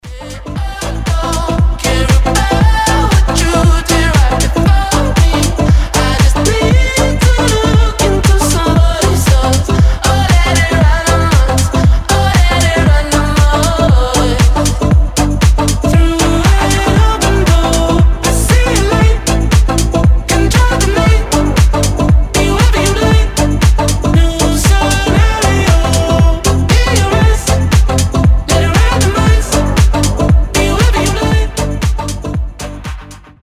EDM
house